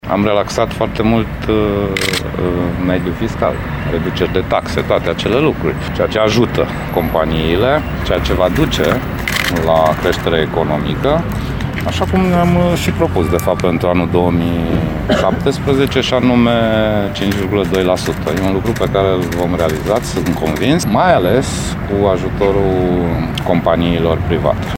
Premierul Sorin Grindeanu a precizat că Executivul pe care îl conduce va crea cadrul legal pentru ca tot mai multe companii de renume să deschidă linii de producţie în România: